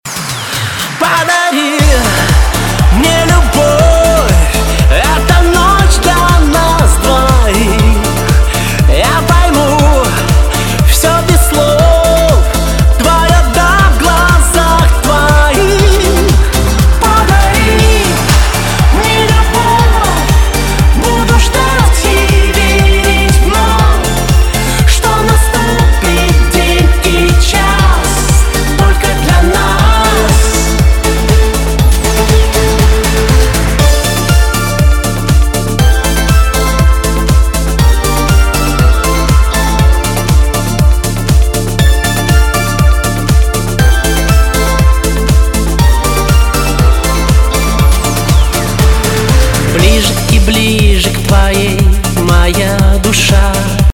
• Качество: 256, Stereo
поп
громкие
remix
Synth Pop